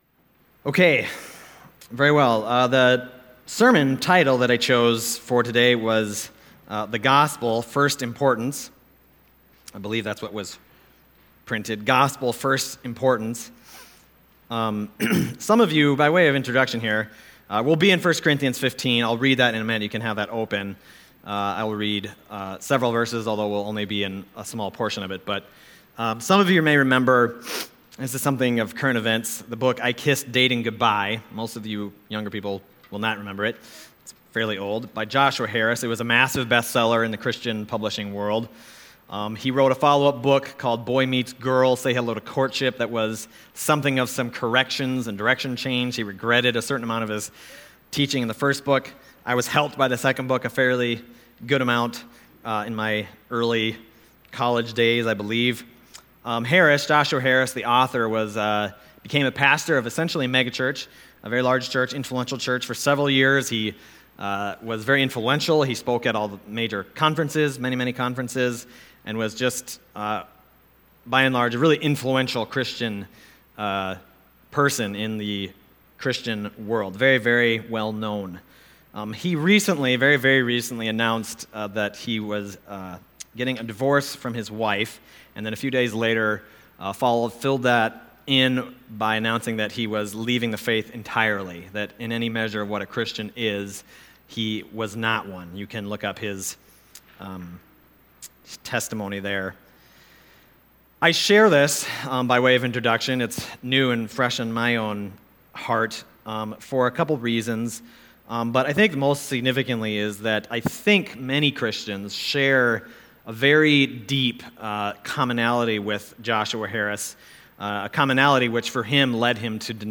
Gospel: First Importance – First Baptist Church – Brookings, South Dakota